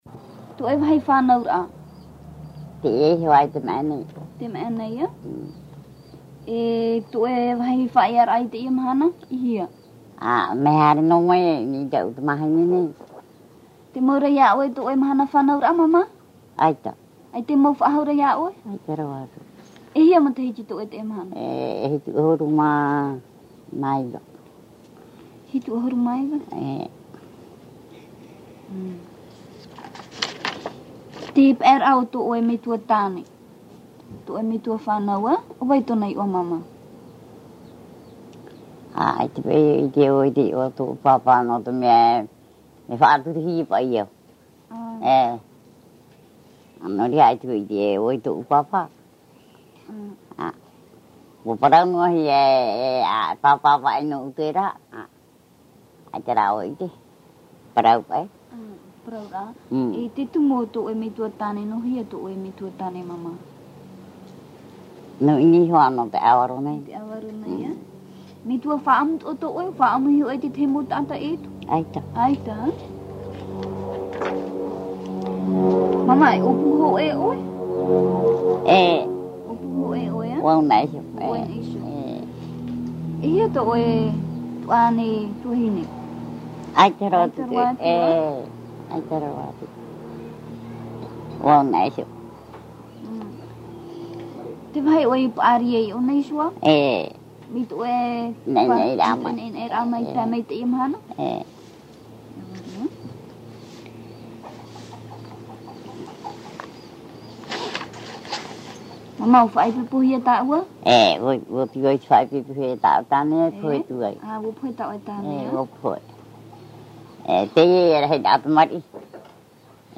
Te ta’ata fa’ati’a / Récit